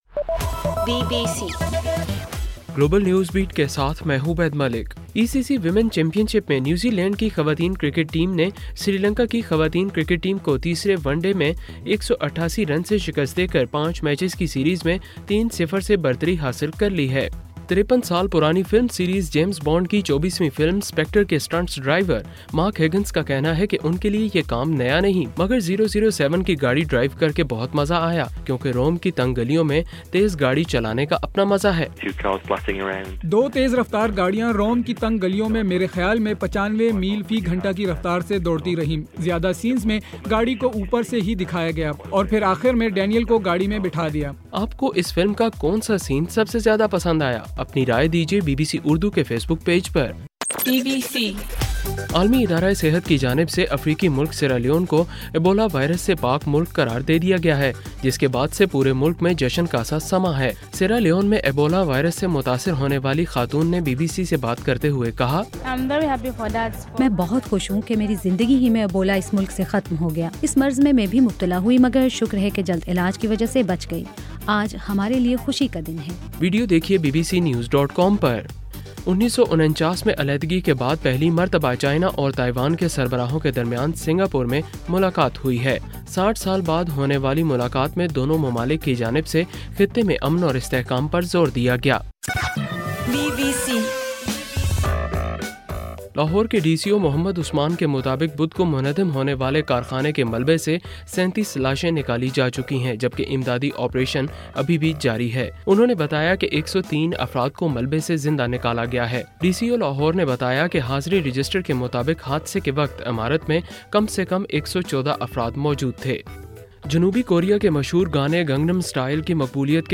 نومبر 7: رات 10 بجے کا گلوبل نیوز بیٹ بُلیٹن